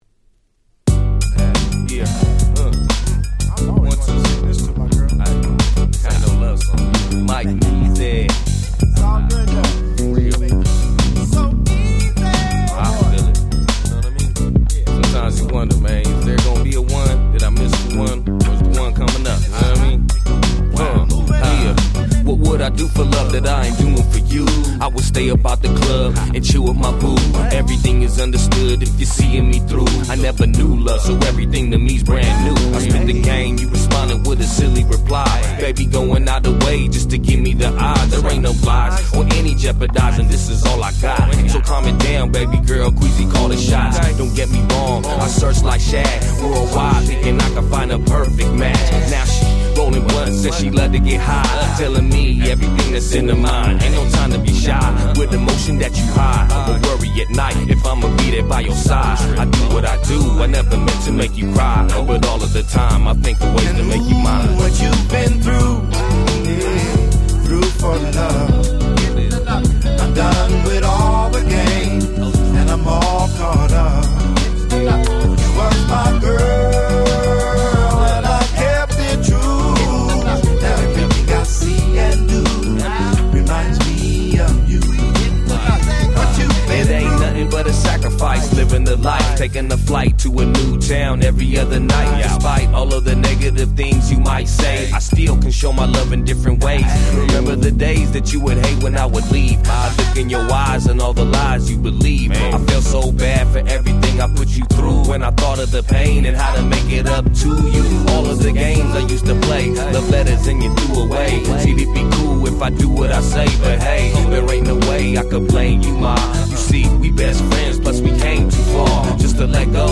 04' Very Nice Chicano Hip Hop !!
キャッチー系 ウエッサイ G-Rap Gangsta Rap